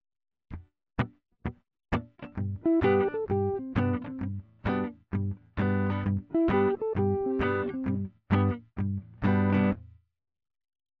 Major melodic fragment